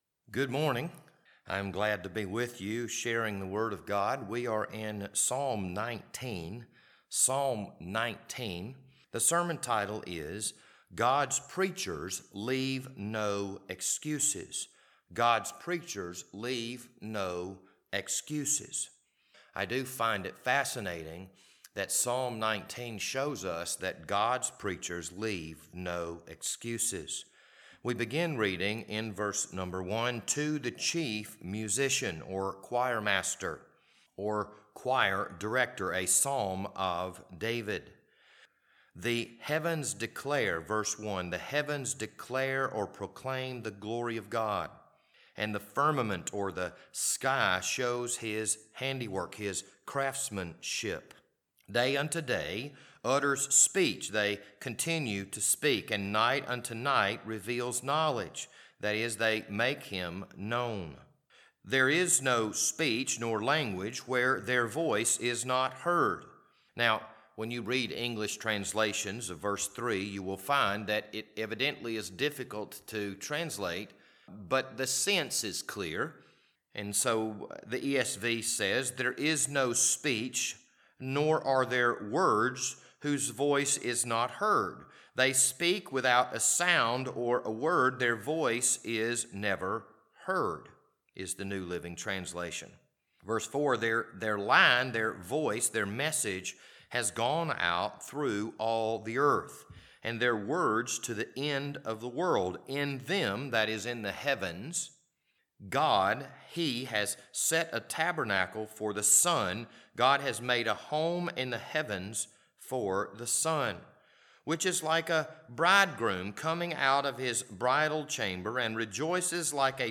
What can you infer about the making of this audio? This Sunday morning sermon was recorded on June 12th, 2022.